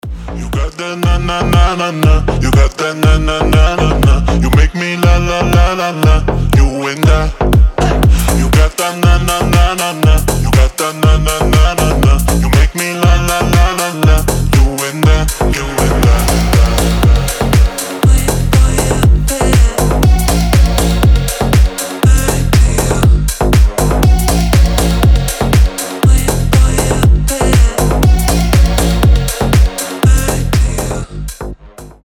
• Качество: 320, Stereo
мужской голос
громкие
EDM
басы
slap house
Зажигательный рингтон, что ещё сказать